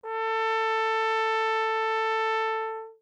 TenorTrombone.mp3